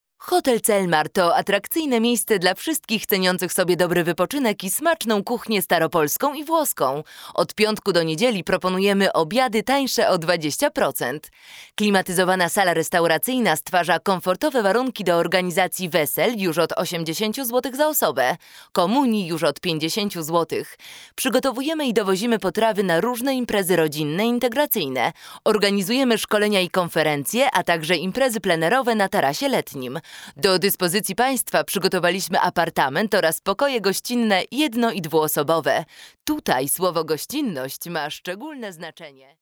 Sprecherin polnisch
Sprechprobe: eLearning (Muttersprache):
female voice over artist polish